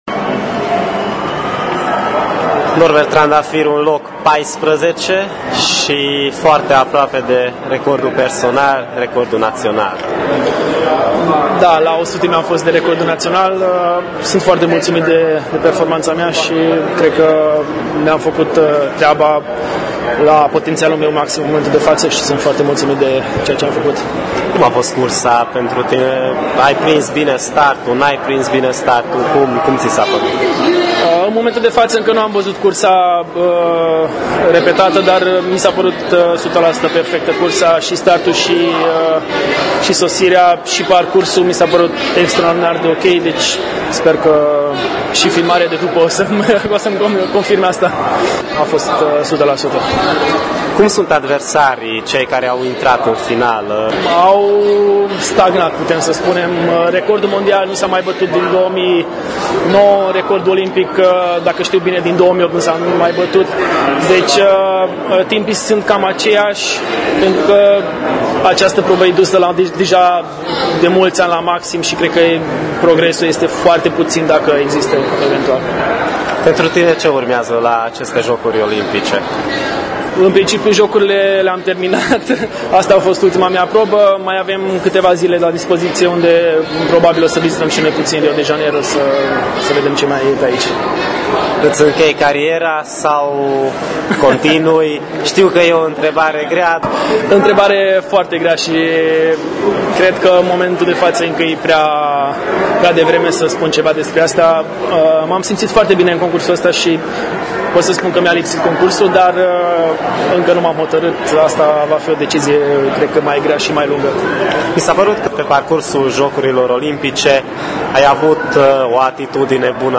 Un interviu audio cu înotătorul mureșean